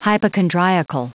Prononciation du mot hypochondriacal en anglais (fichier audio)
Prononciation du mot : hypochondriacal